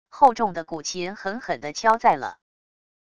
厚重的古琴狠狠的敲在了wav下载